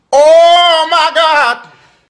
nanocloud shared/games/Titanfall2/R2Titanfall/mods.good/Goofy ahh sounds/audio/death_pinkmist
OH_MY_GOD.wav